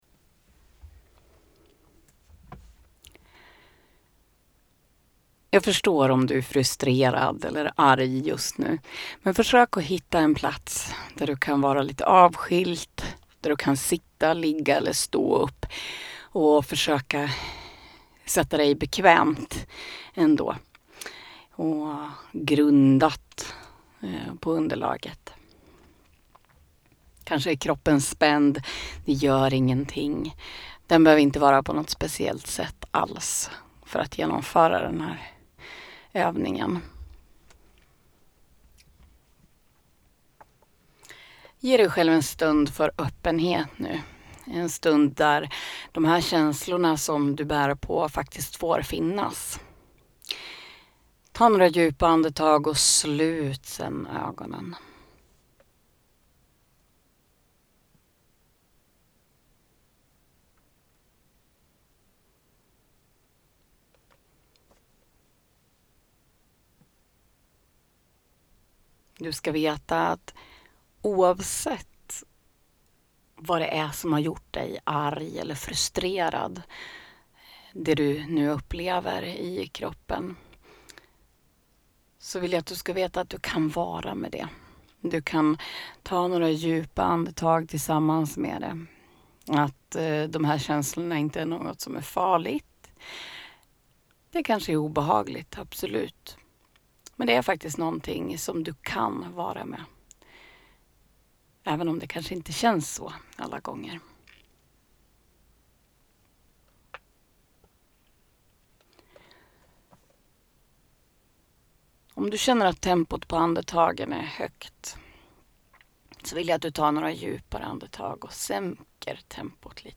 Avslappning_frustration_generell.mp3